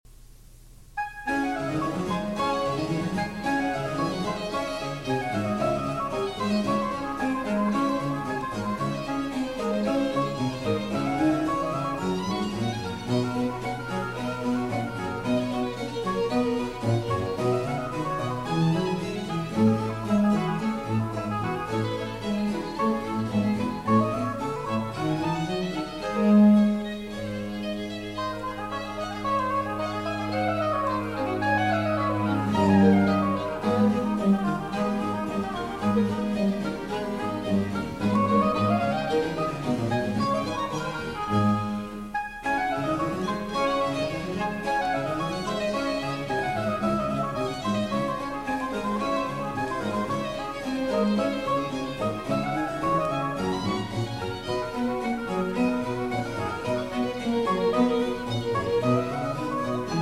Oboe
Harpsichord
Cello